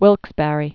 (wĭlksbărē, -bărə)